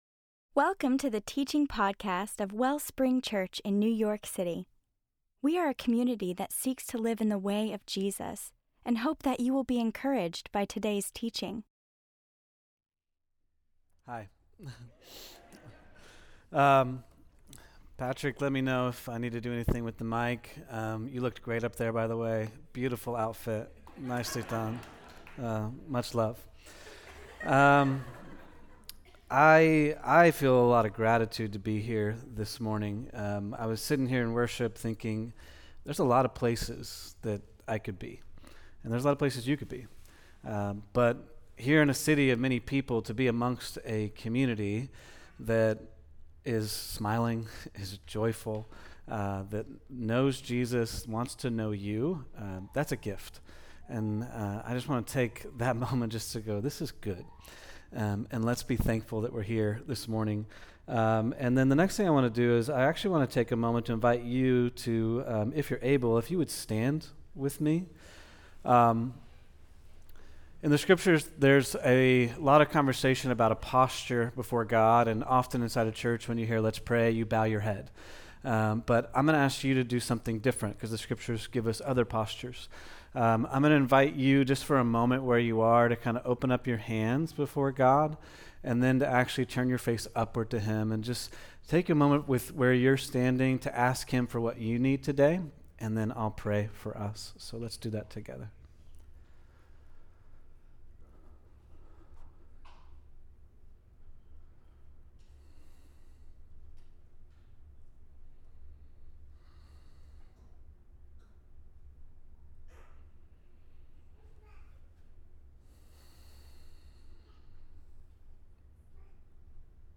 WAIT ON THE WIND: THE MESSINESS OF GROWTH (GUEST SPEAKER